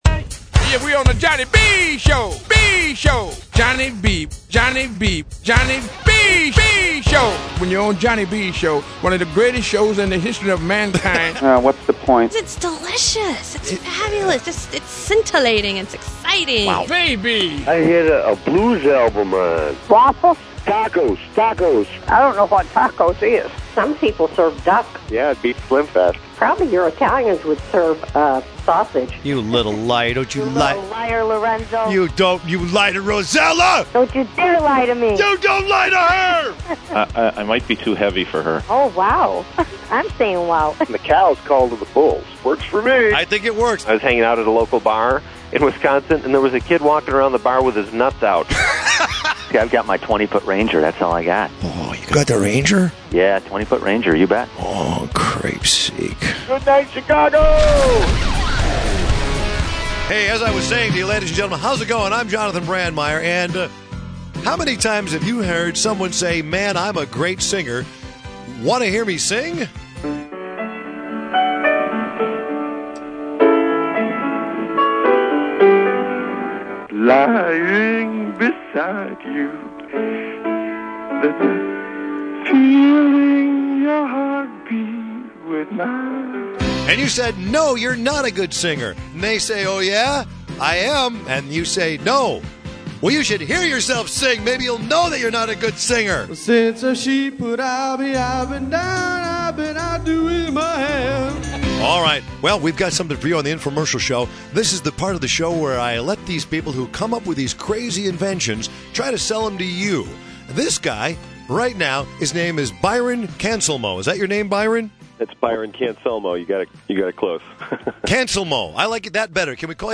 WLUP . Click H E R E to listen to that radio interview!!!